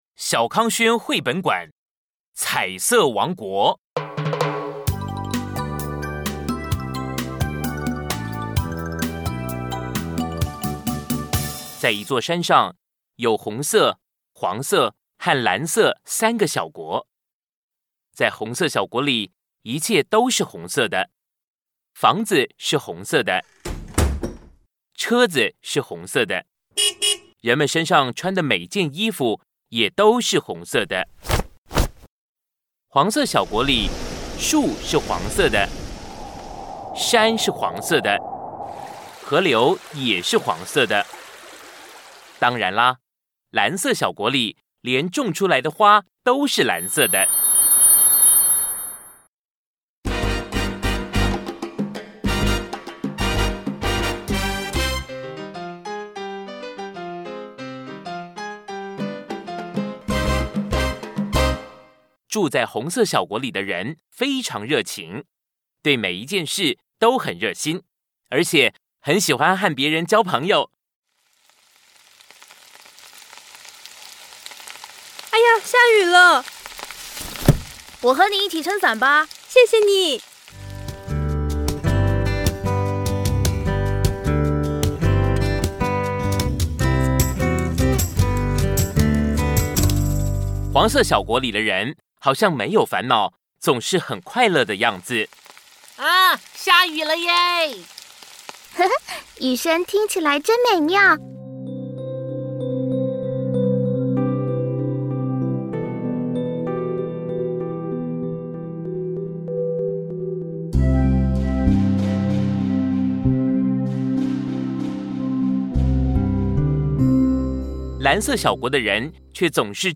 MN SF AB 01 Audiobooks Male Mandarin